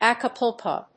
/ὰːkəpúːlkoʊ(米国英語), `ækəpˈʊlkəʊ(英国英語)/
フリガナアッカプルコー